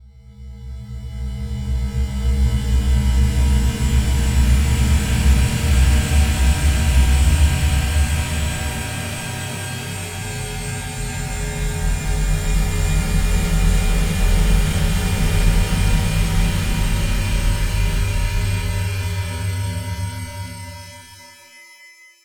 Spectral Drone 03.wav